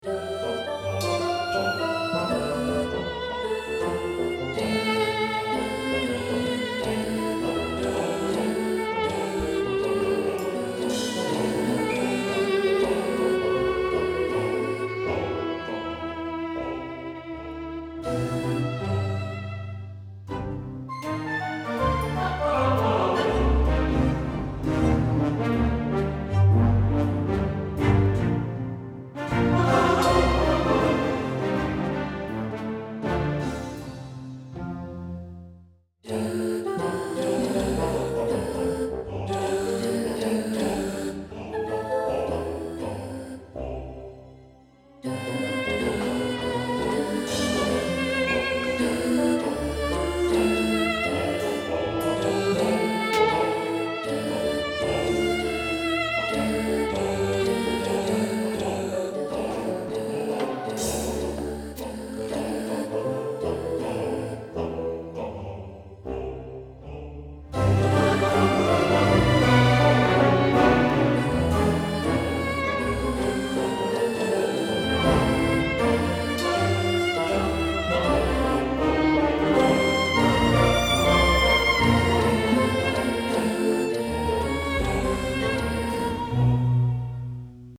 Orchestra & Combo